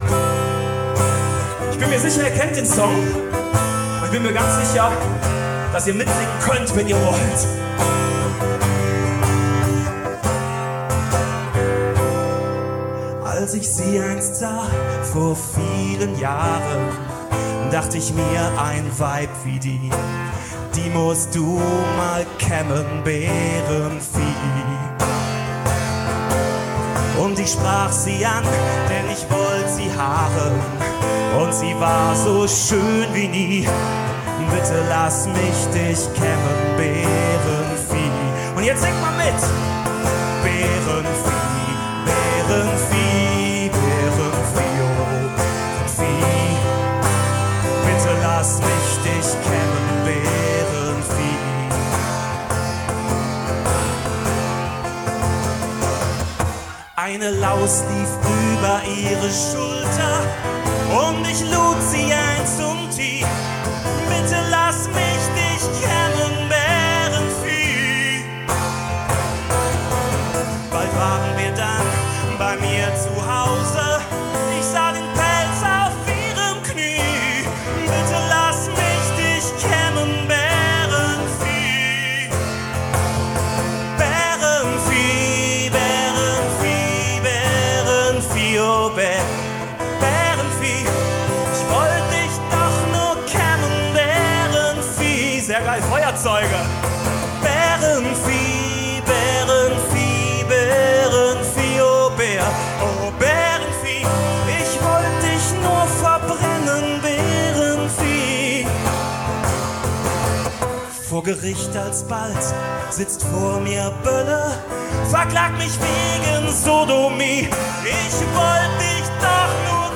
Live 2003 Harmonie/Bonn, todesmutig unplugged